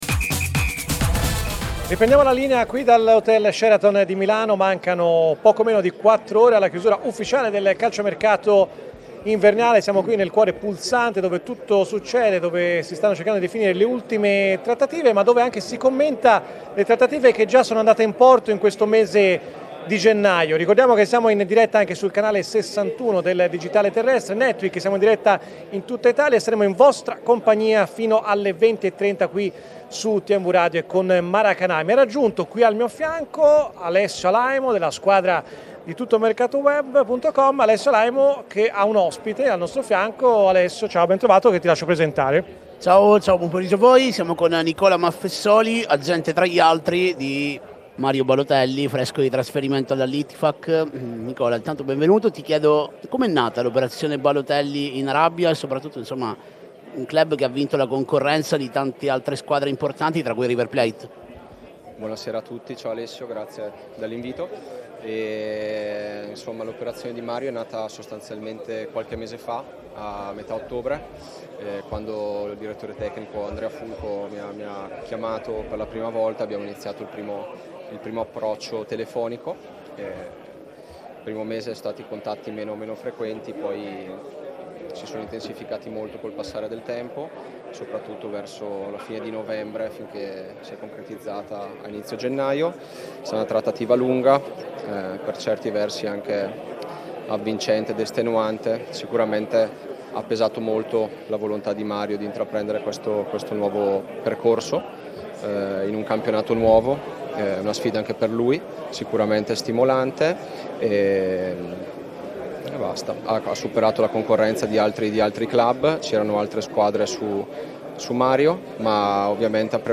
è stato ospite di TMW Radio.